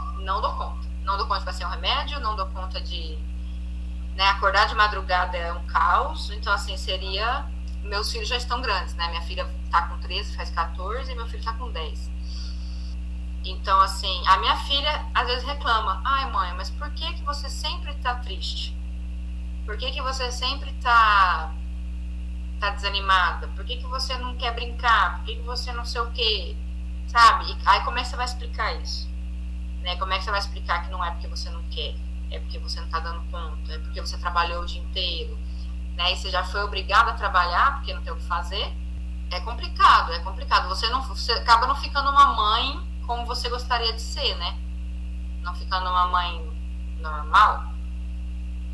Depoimento completo